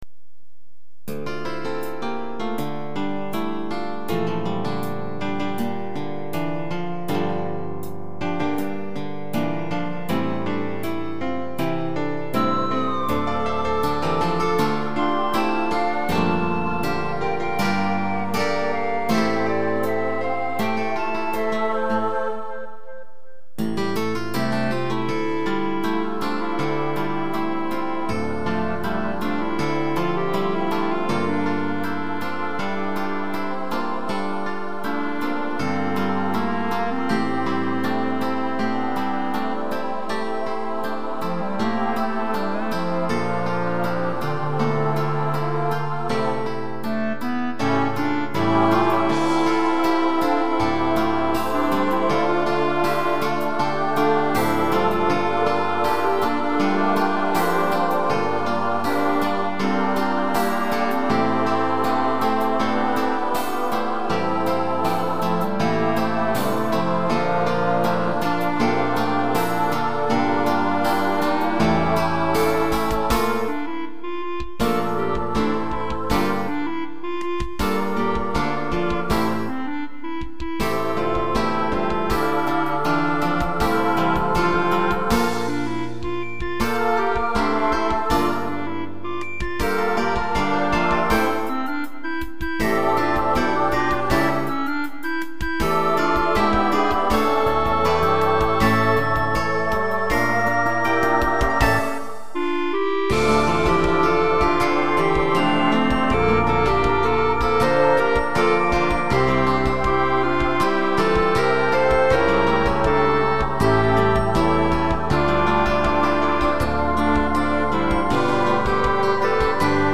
インストver。